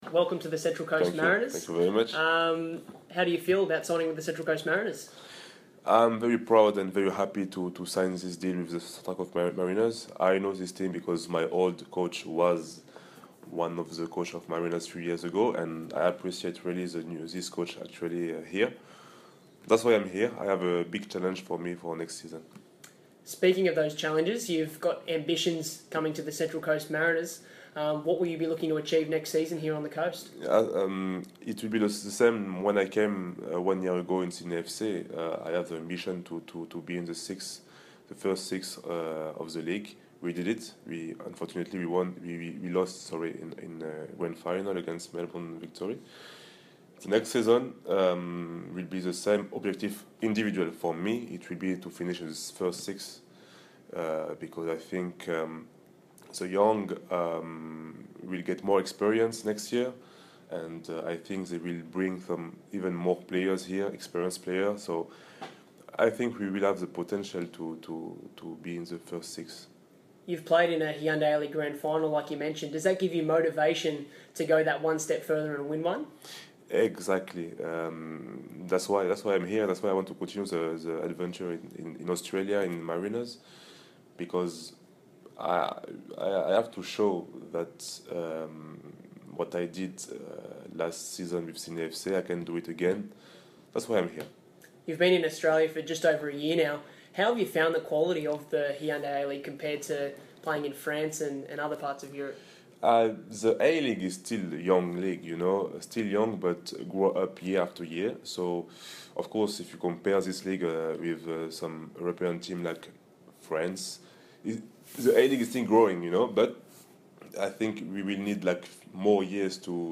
INTERVIEW: Jacques Faty's first interview with the Mariners